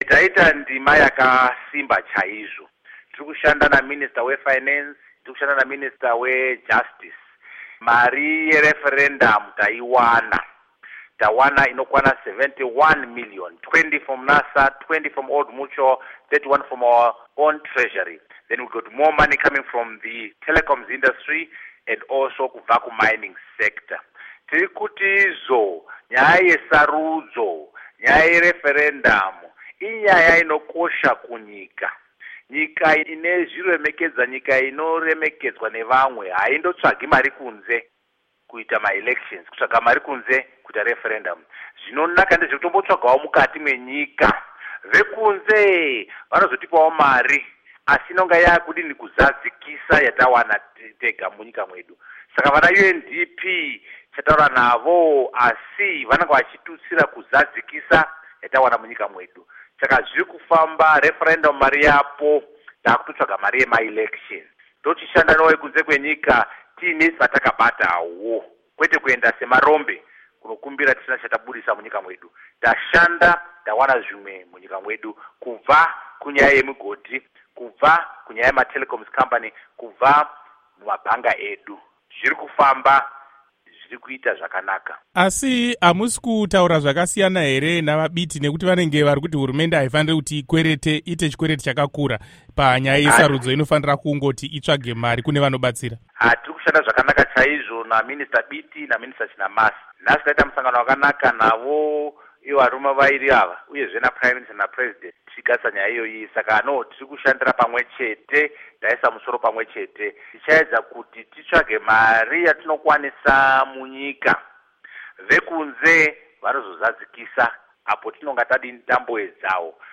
Hurukuro naVaArthur Mutambara